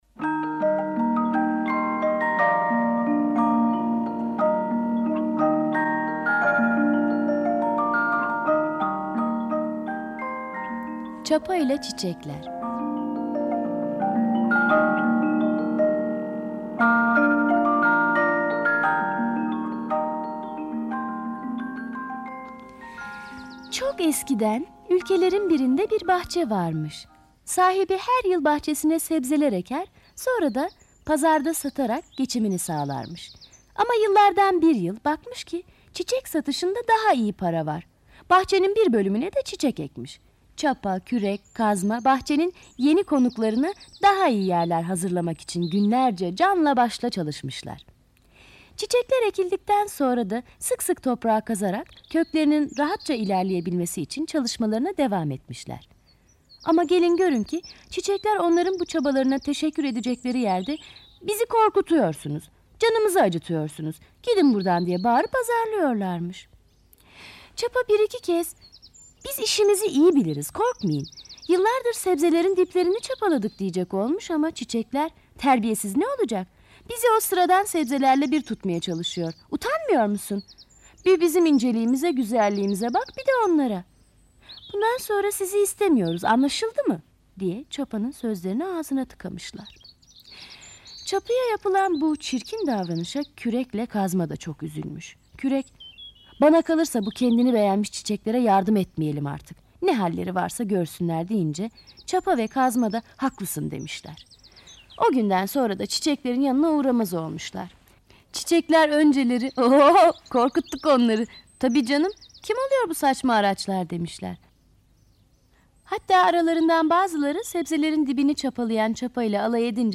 Çapa ile çiçekler sesli masalı, mp3 dinle indir
Sesli Çocuk Masalları